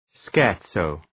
Προφορά
{‘skeər,tsəʋ}